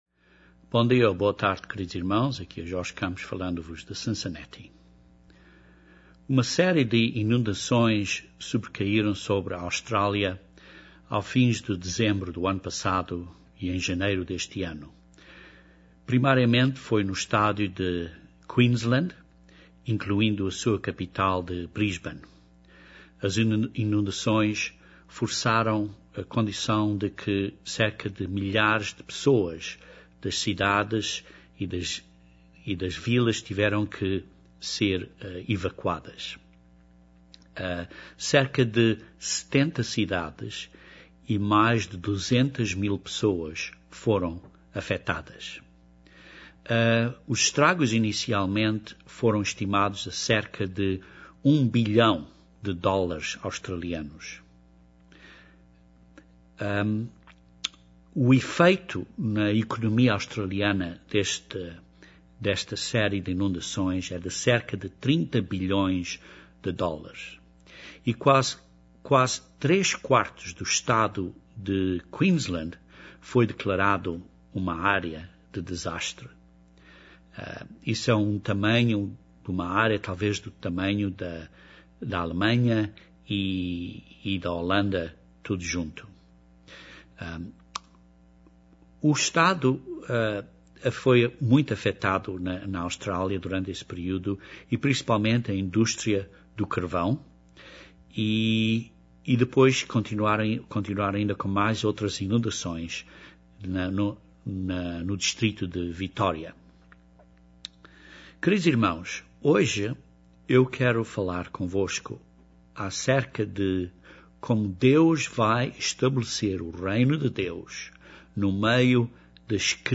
Este sermão descreve acontecimentos até o Reino de Deus ser estabelecido na Terra.